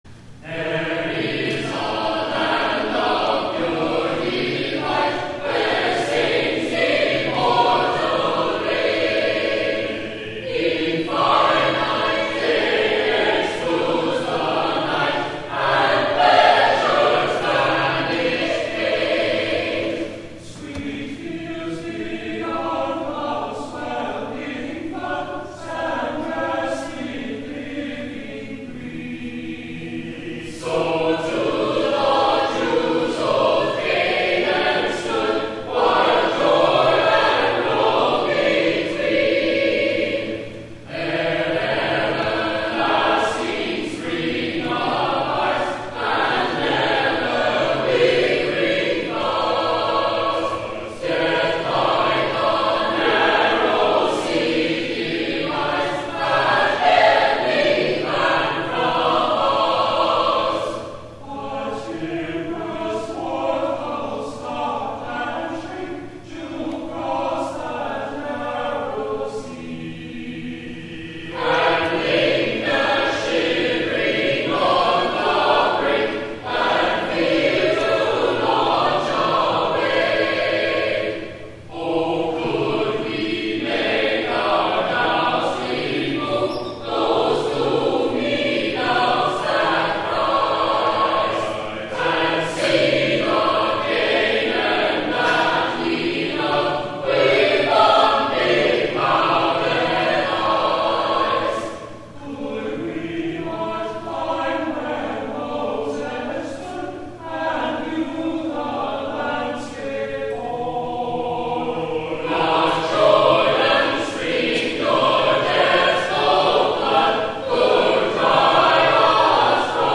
THE ANTHEM